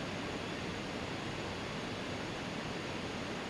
BackSound0456.wav